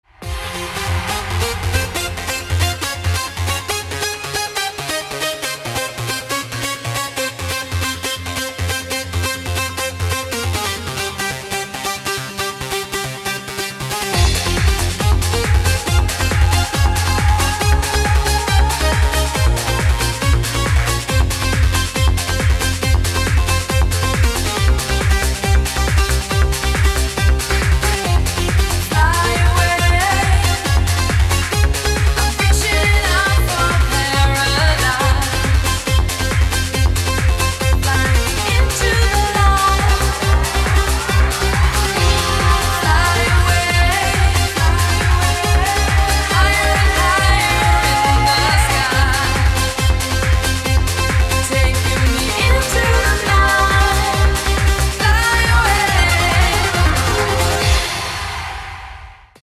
• Качество: 256, Stereo
громкие
dance
Electronic
электронная музыка
club
красивый женский голос
Trance